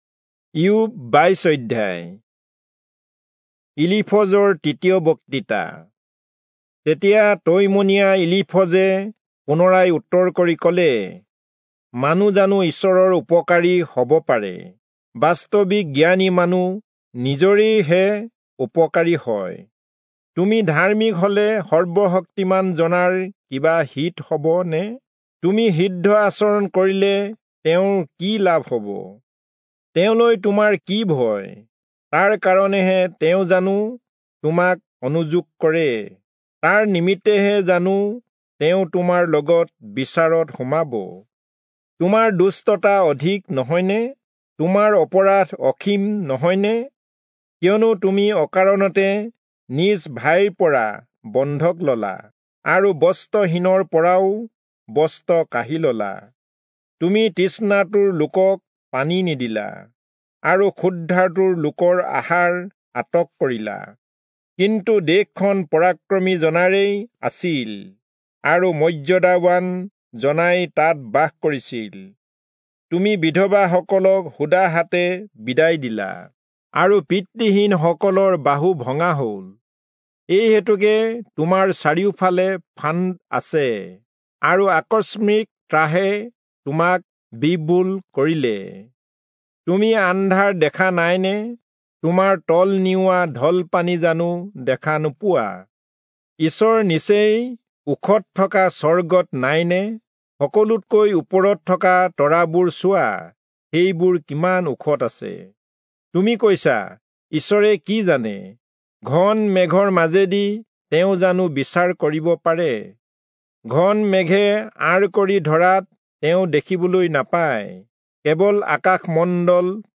Assamese Audio Bible - Job 32 in Ocvml bible version